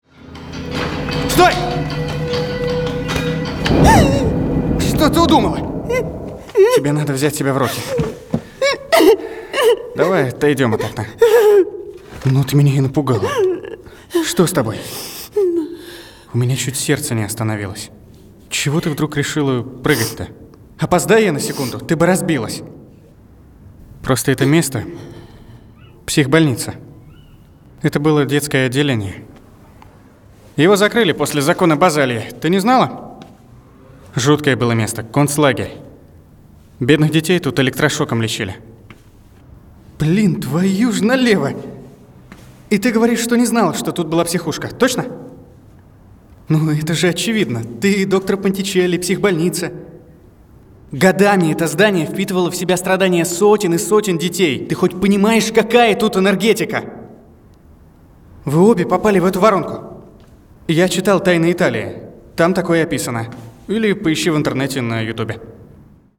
Дубляж
Муж, Другая
SE X1, Long VoiceMaster, Scarlett 2i2